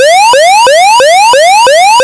alarm (1).wav